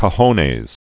(kə-hōnāz, kō-hōnĕs)